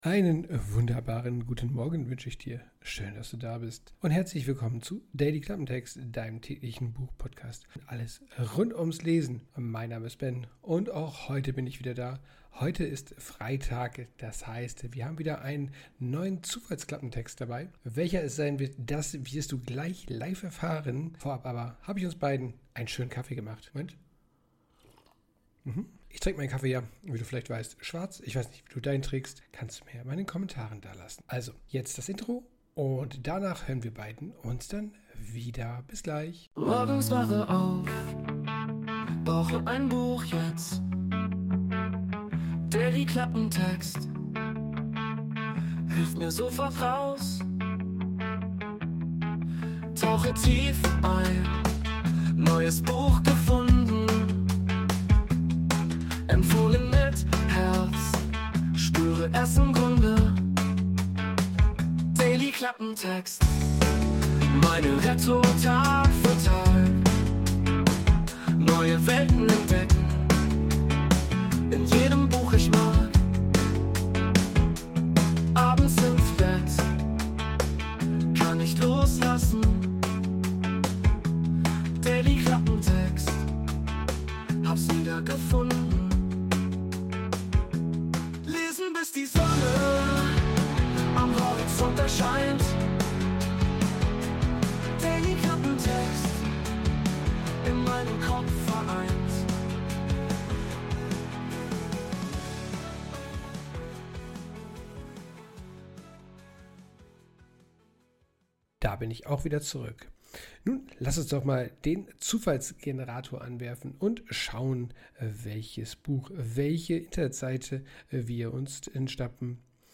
Intromusik: Wurde mit der KI Suno erstellt.